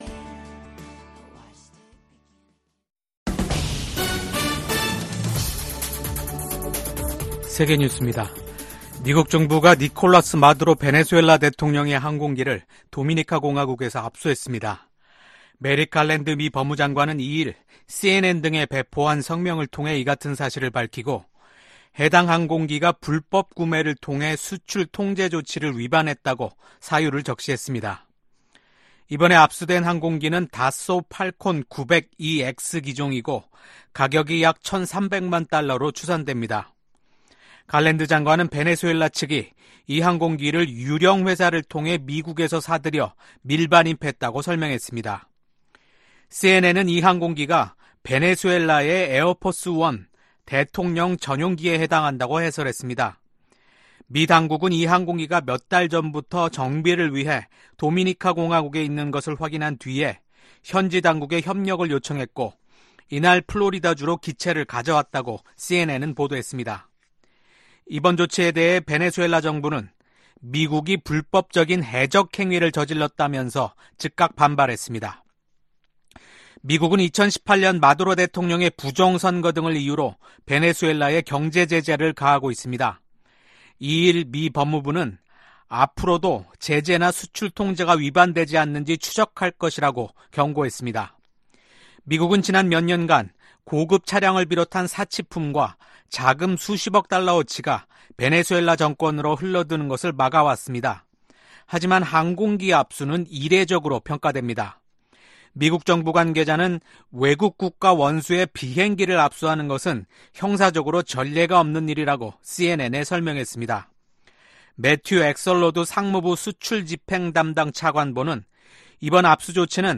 VOA 한국어 아침 뉴스 프로그램 '워싱턴 뉴스 광장' 2024년 9월 4일 방송입니다. 신종코로나바이러스 사태 이후 최대 규모의 백신 접종 캠페인이 북한 전역에서 시작됐습니다. 유엔 사무총장이 북한의 열악한 인권 상황을 거듭 우려하면서 인권 유린 가해자들을 처벌하라고 촉구했습니다.